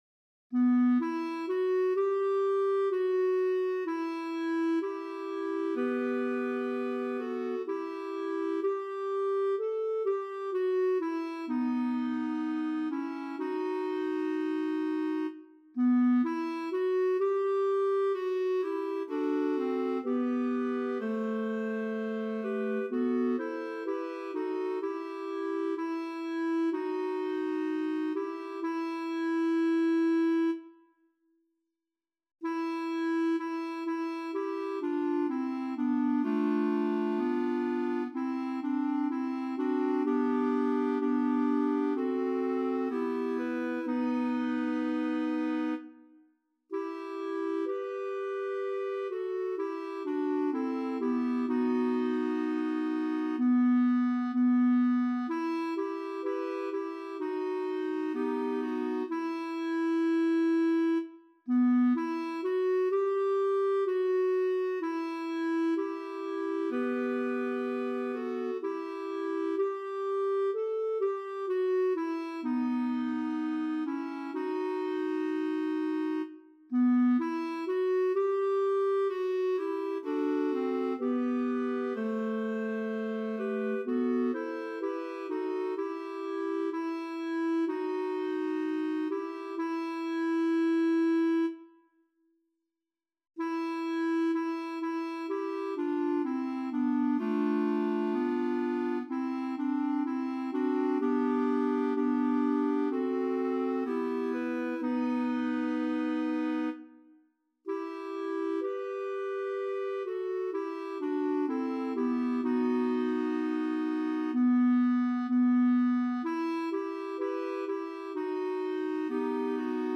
Language: Portuguese Instruments: Organ